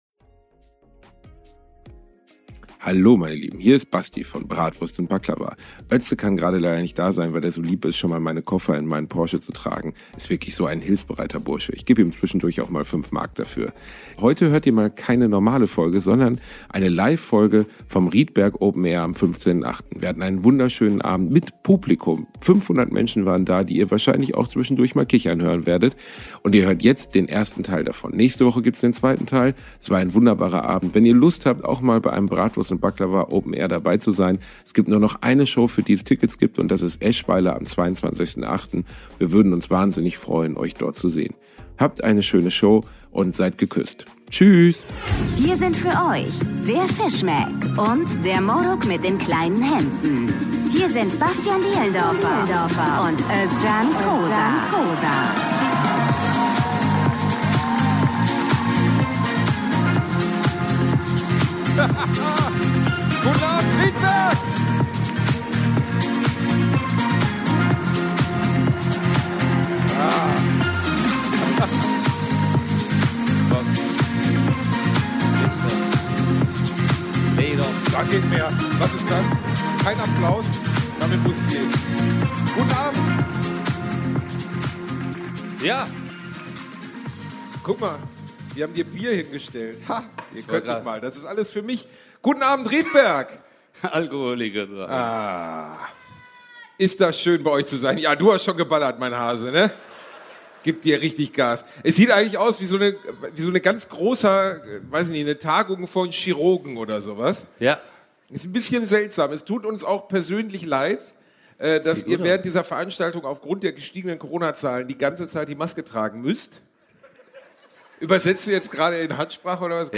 #107 Die Nicht-Amigos LIVE in Rietberg 1 ~ Bratwurst und Baklava - mit Özcan Cosar und Bastian Bielendorfer Podcast